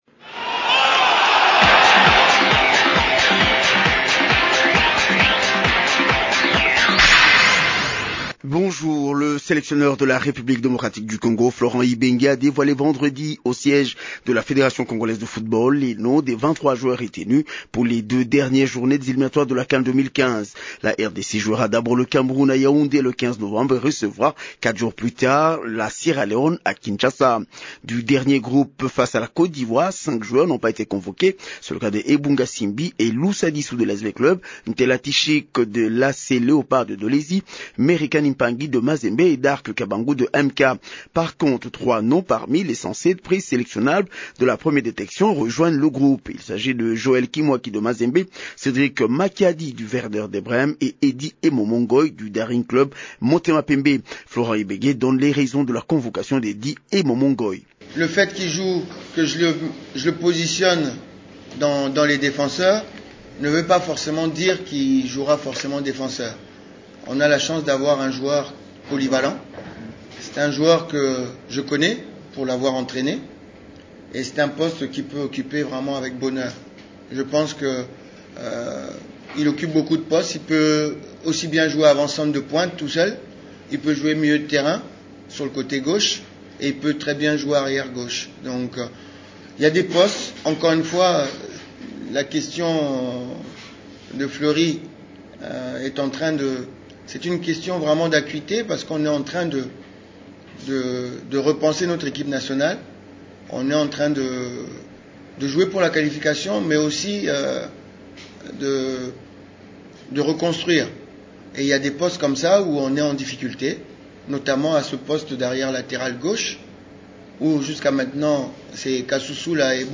Journal des sports du 7 novembre 2014
Dans ce journal, Florent Ibenge donne les raisons de son choix sur les appelés.